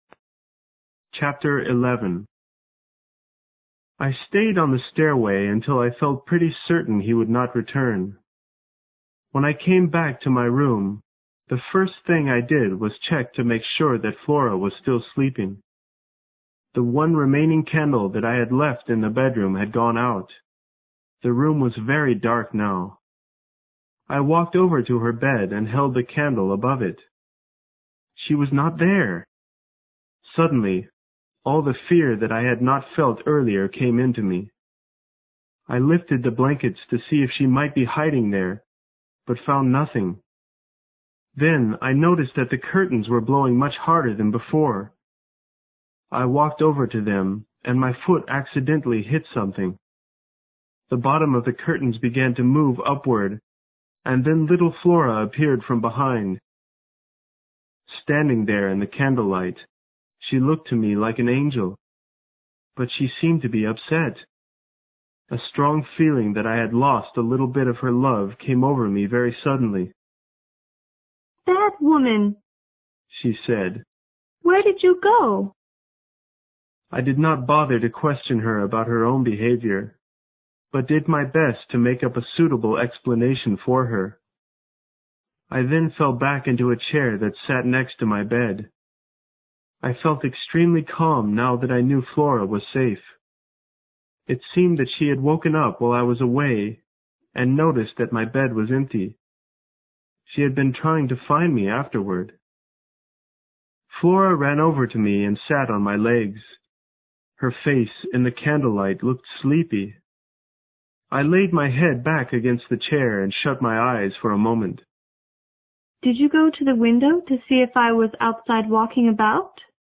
有声名著之螺丝在拧紧chapter11 听力文件下载—在线英语听力室